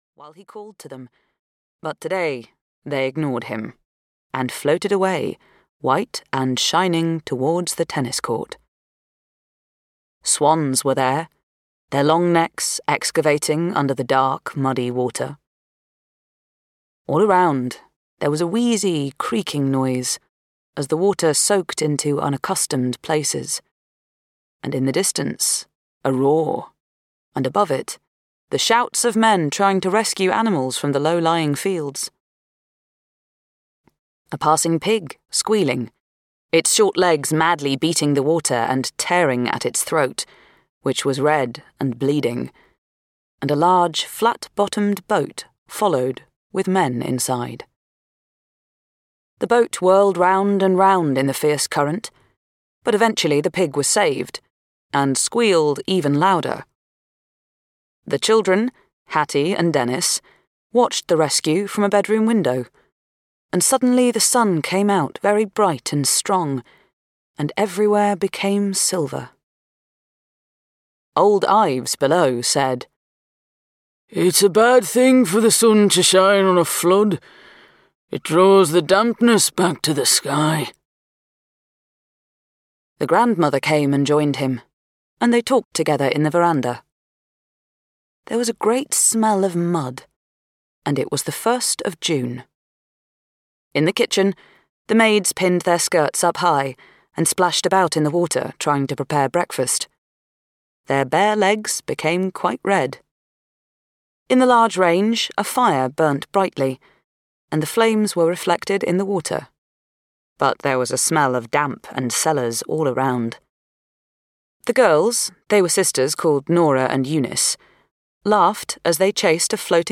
Audio knihaWho Was Changed and Who Was Dead (EN)
Ukázka z knihy